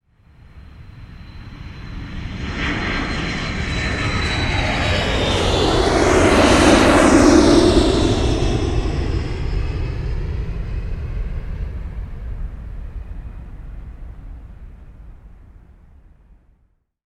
jetApproach.wav